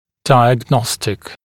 [ˌdaɪəg’nɔstɪk][ˌдайэг’ностик]диагностический; определяющий, отличительный; симптоматический